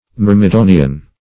Myrmidonian \Myr`mi*do"ni*an\, a.